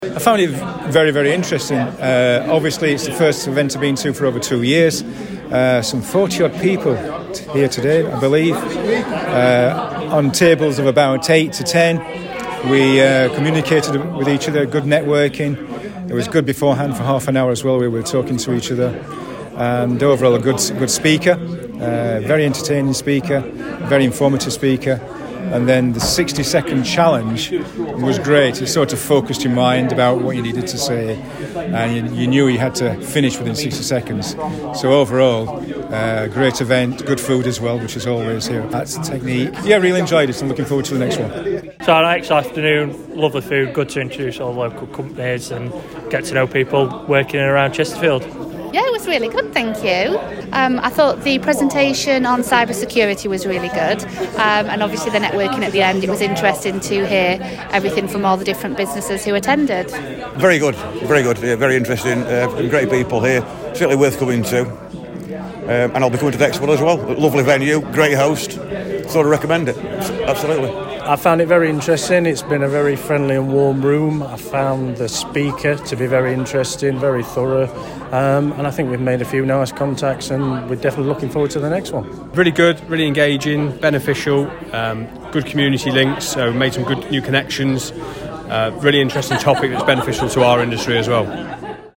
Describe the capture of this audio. Last Friday’s Inspire Networking lunch, held in the 1866 Brampton Brewery Lounge, proved to be a great success, with over 40 people in attendance.